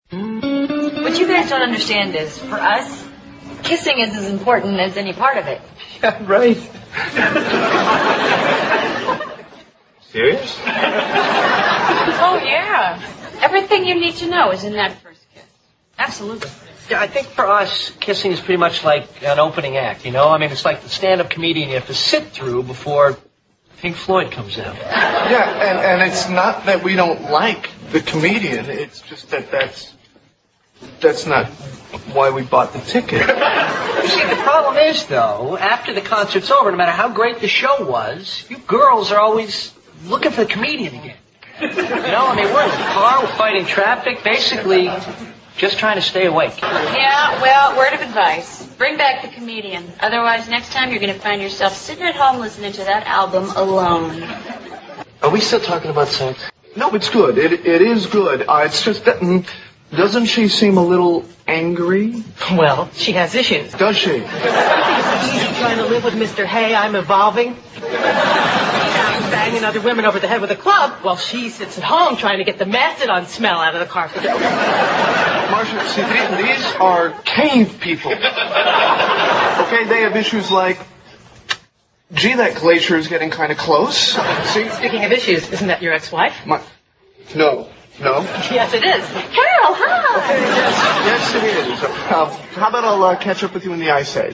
在线英语听力室老友记精校版第1季 第13期:参加助产培训班(1)的听力文件下载, 《老友记精校版》是美国乃至全世界最受欢迎的情景喜剧，一共拍摄了10季，以其幽默的对白和与现实生活的贴近吸引了无数的观众，精校版栏目搭配高音质音频与同步双语字幕，是练习提升英语听力水平，积累英语知识的好帮手。